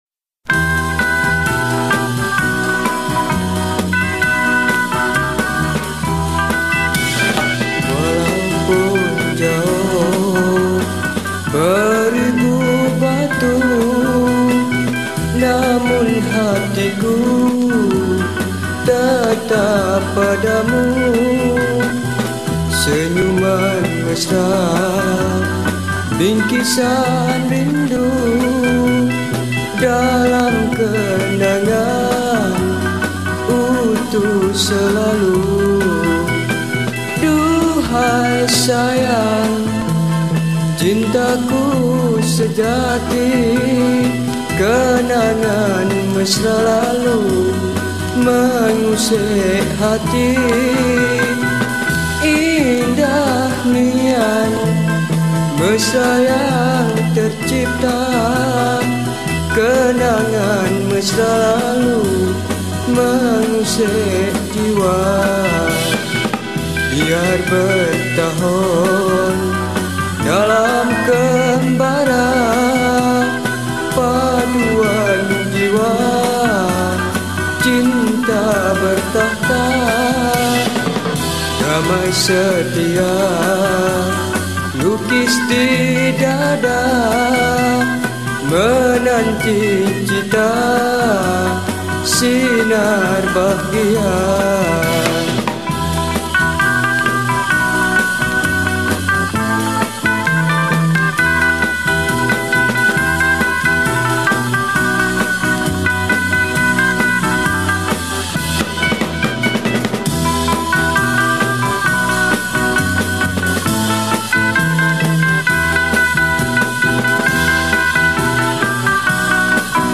Chords : Em